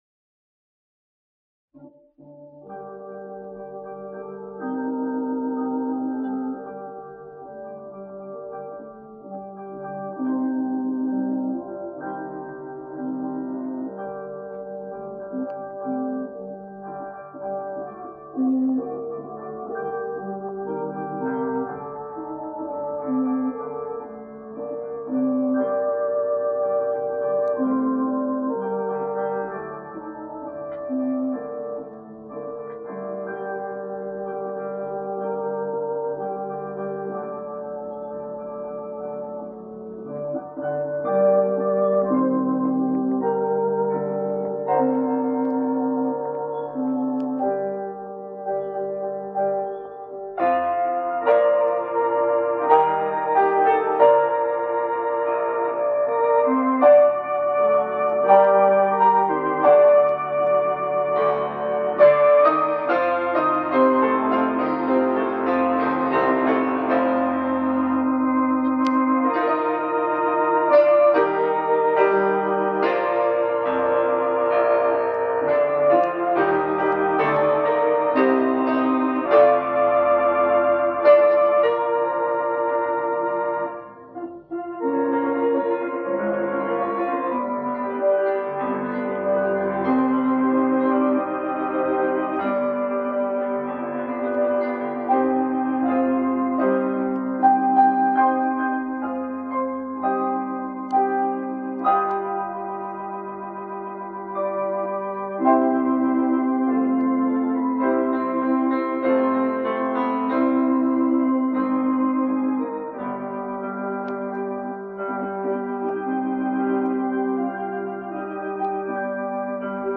organist
pianist